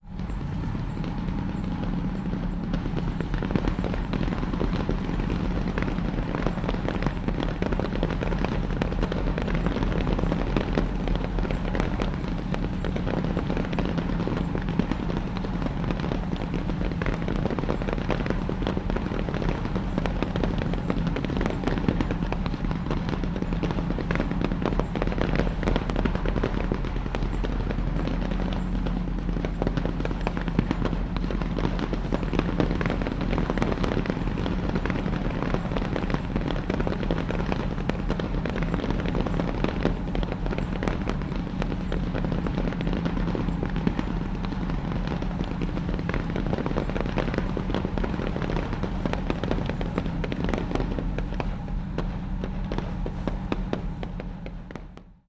Amphibious assault occurs with lots of gun fire and boat engines in background. Gunfire, Assault War, Explosion Invasion, Amphibious